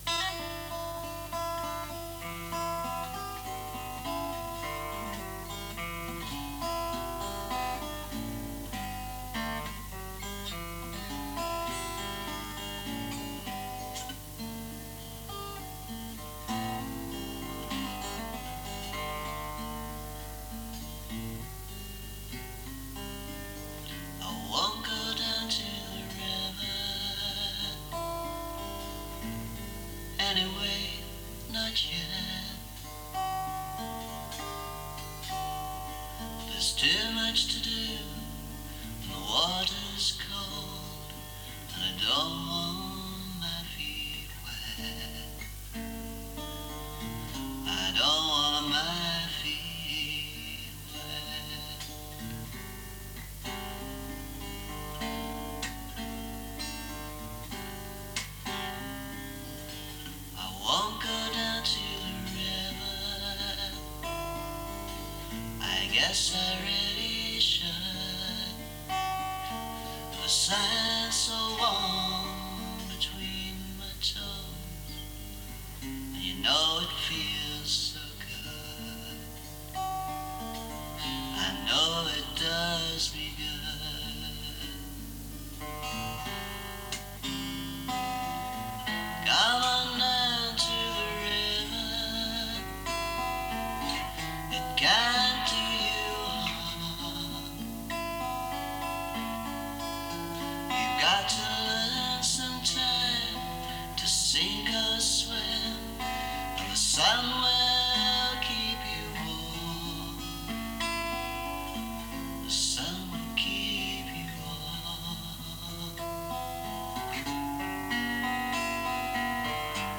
This demo was recorded back in the 80s, and the voice was in better shape.